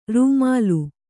♪ rūmālu